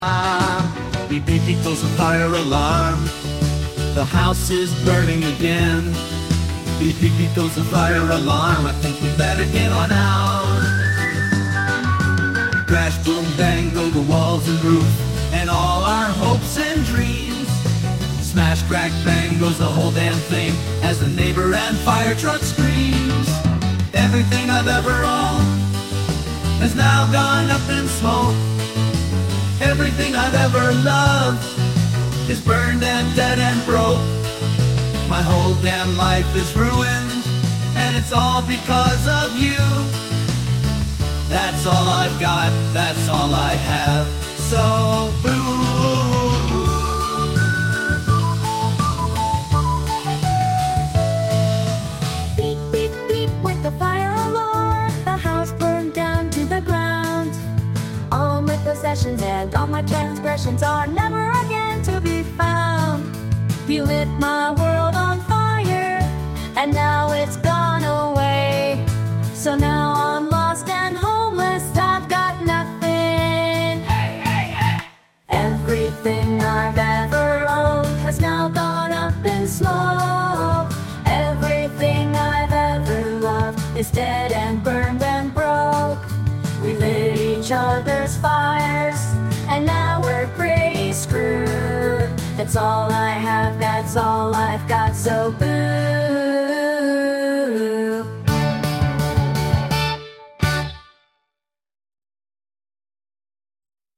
As a quick test, I let two local LLMs ramble about random topics of their choice and generated audio using zero-shot voice cloning with Chatterbox-Turbo.